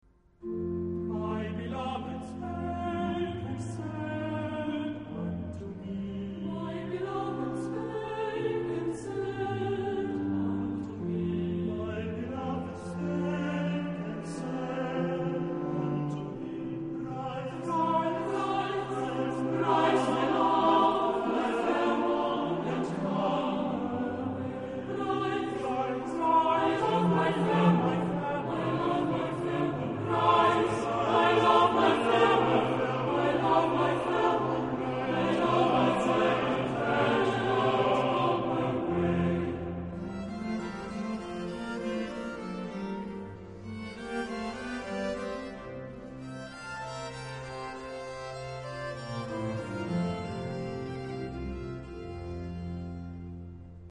SATBB (5 voces Coro mixto) ; Partitura general.
Verse Anthem. Barroco.
Himno (sagrado).